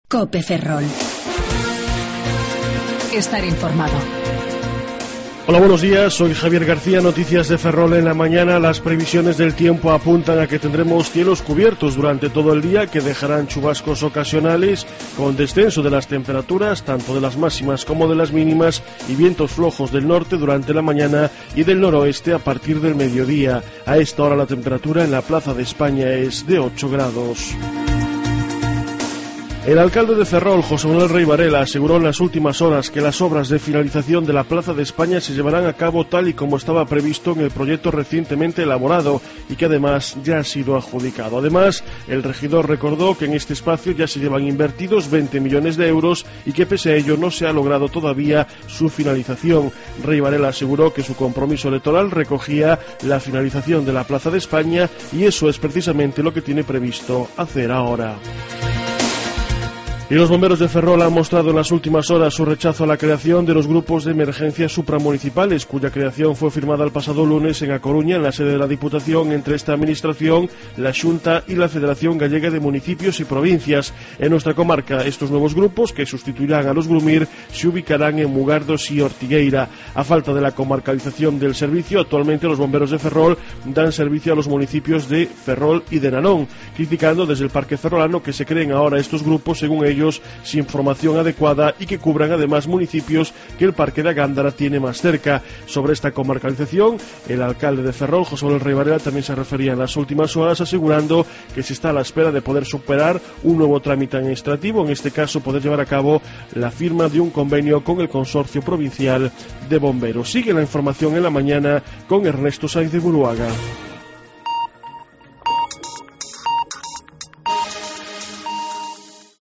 07:28 Informativo La Mañana